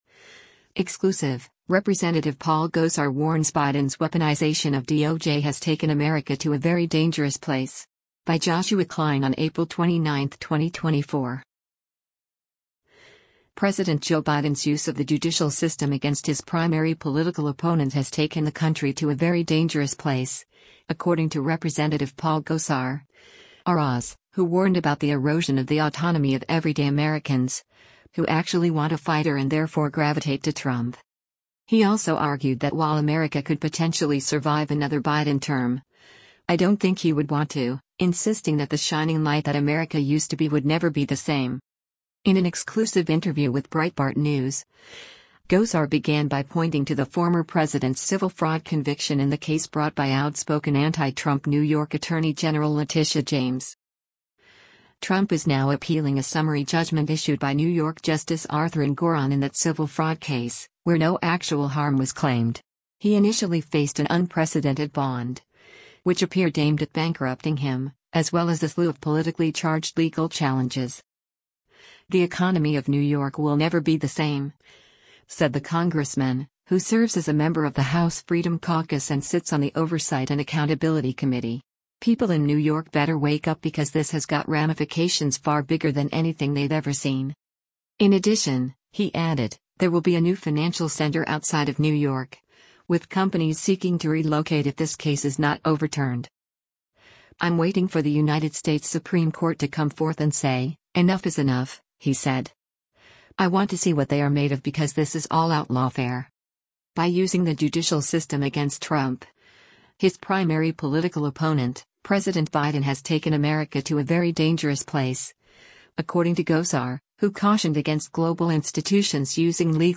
In an exclusive interview with Breitbart News, Gosar began by pointing to the former president’s civil fraud conviction in the case brought by outspoken anti-Trump New York Attorney General Letitia James.